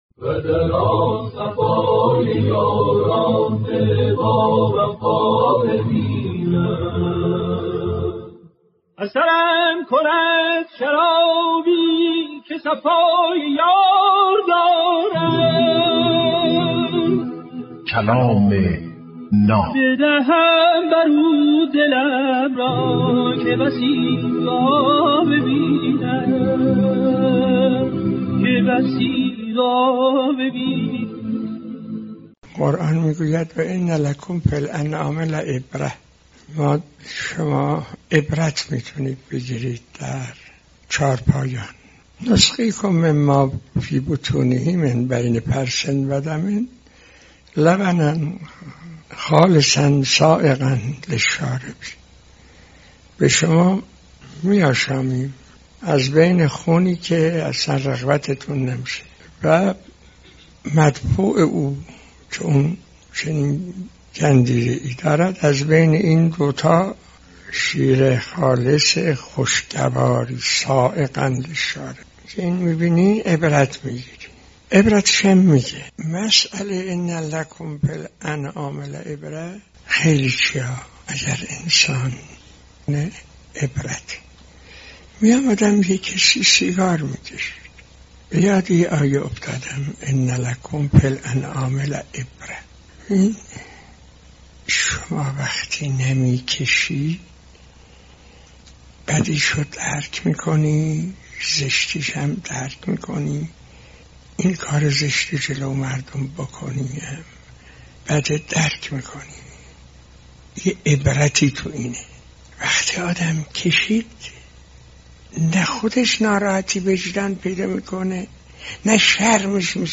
کلام ناب برنامه ای از سخنان بزرگان است که هر روز ساعت 07:10 به وقت افغانستان به مدت 8دقیقه پخش می شود